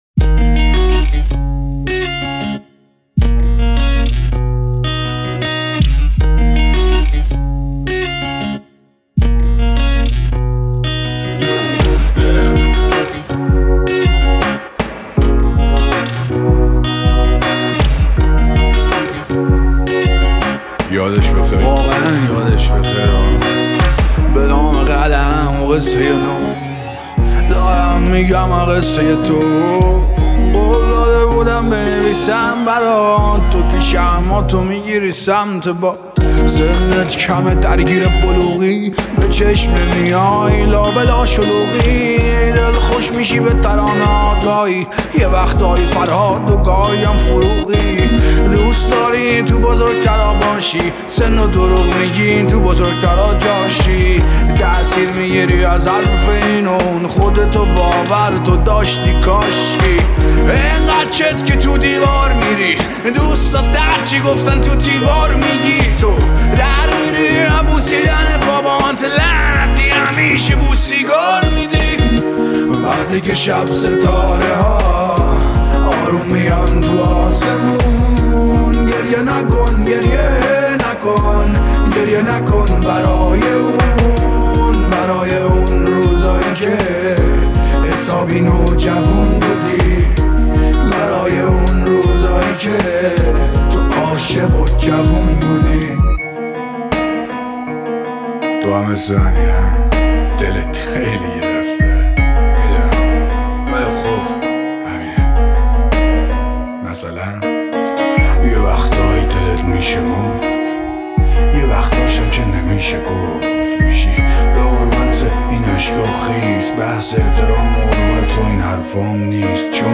متن این ترانه رپ غمگین :